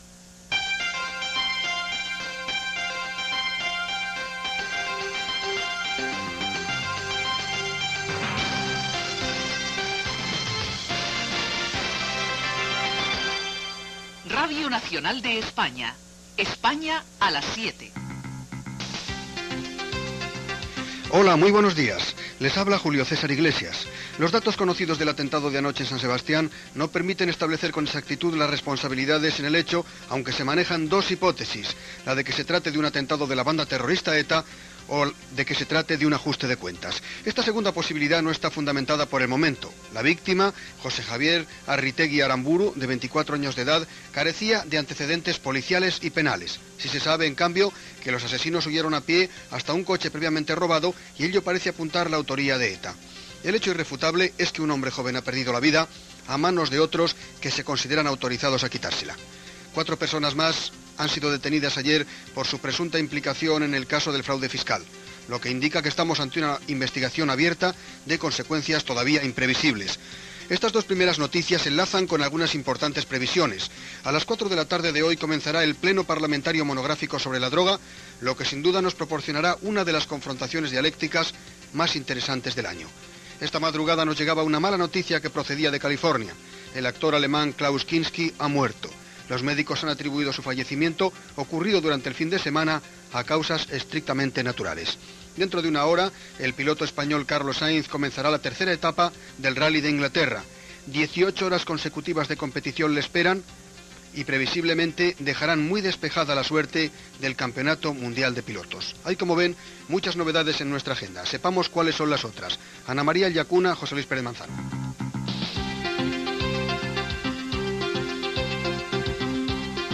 Careta del programa, sumari: atemptat a Donòstia, detencions pel frau fiscal, ple parlamentari sobre la droga. Titulars del dia.
Informatiu